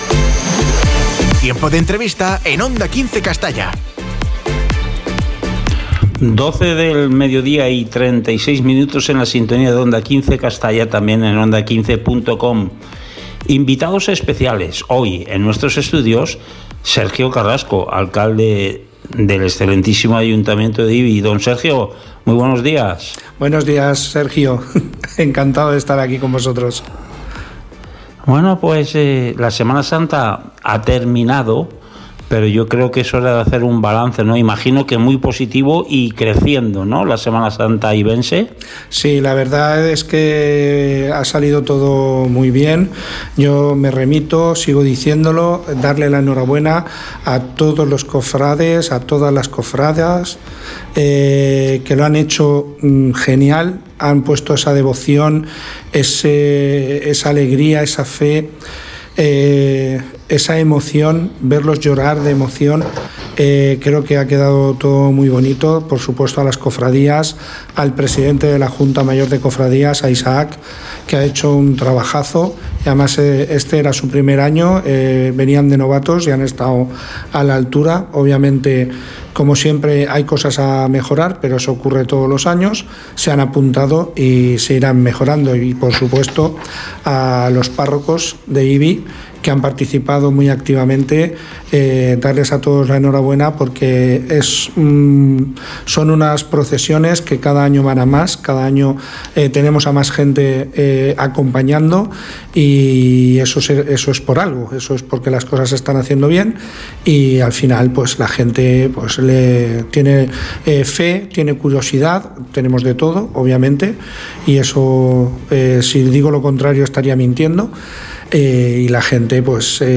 Entrevista a Sergio Carrasco, Alcalde del Excelentísimo Ayuntamiento de Ibi - Onda 15 Castalla 106.0 FM
En nuestro Informativo, hablamos con Sergio Carrasco, alcalde del Excelentísimo Ayuntamiento de Ibi.